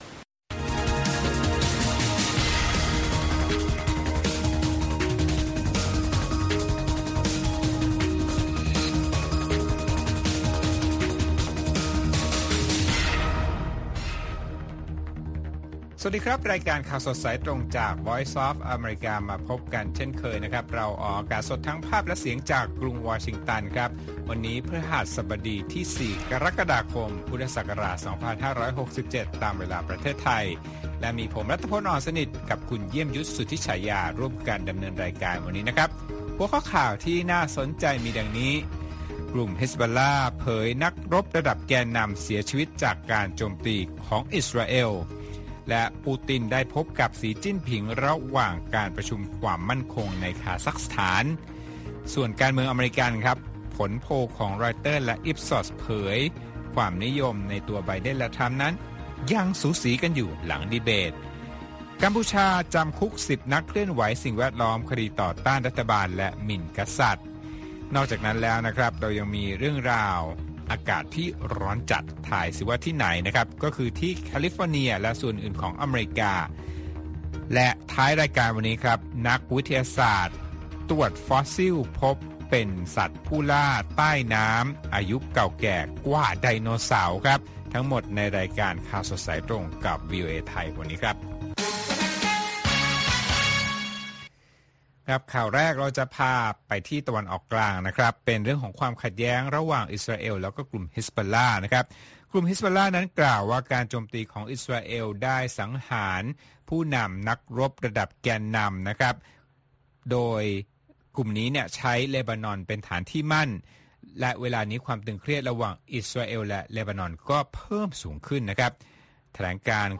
ข่าวสดสายตรงจากวีโอเอ ไทย ประจำวันที่ 4 กรกฎาคม 2567